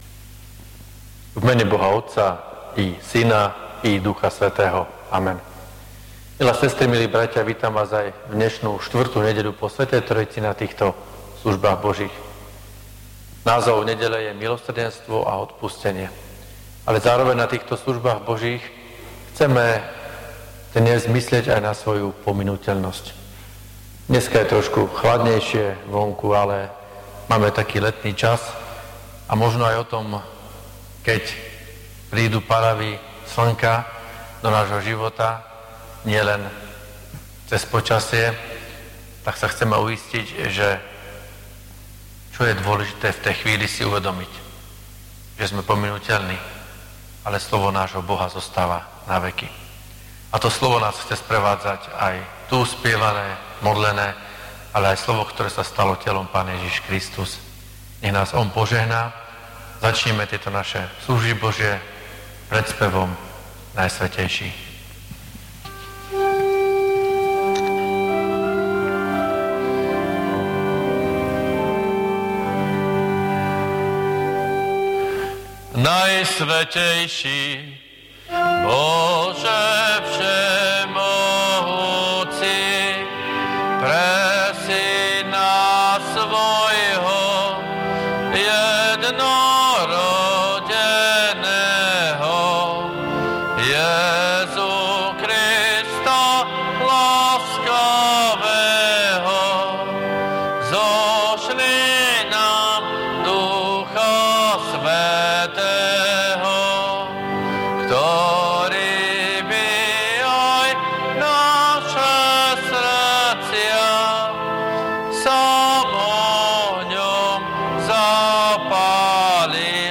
Služby Božie – 4. nedeľa po Sv. Trojici
V nasledovnom článku si môžete vypočuť zvukový záznam zo služieb Božích – 4. nedeľa po Sv. Trojici.
PIESNE: 365, 550, 203, 490, A88.